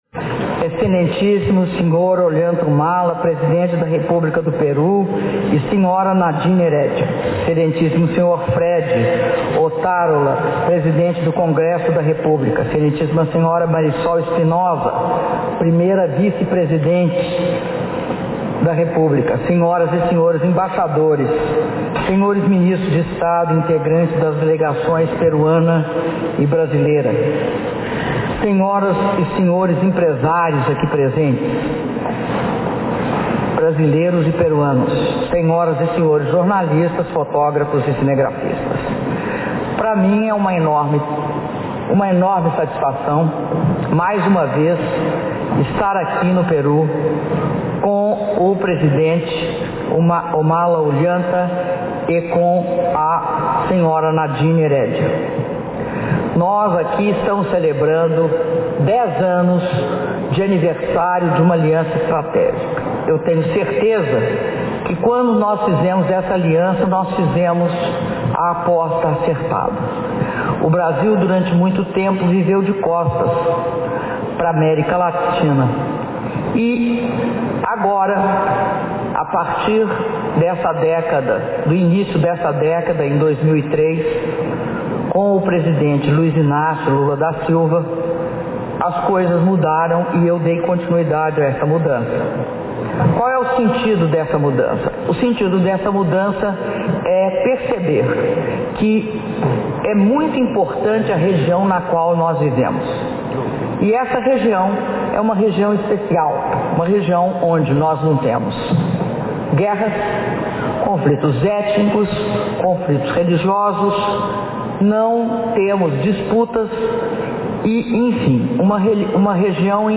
Áudio do brinde da Presidenta da República, Dilma Rousseff, durante almoço oferecido pelo Presidente da República do Peru, Ollanta Humala - Lima/Peru